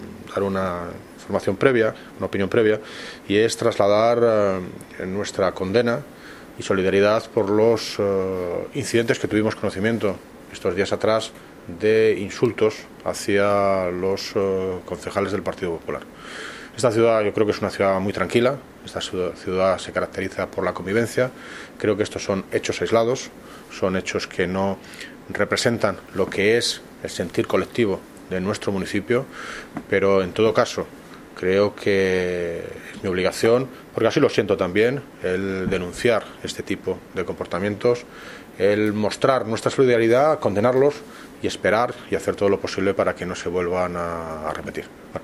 Audio - David Lucas (Alcalde de Móstoles) Sobre Condena insultos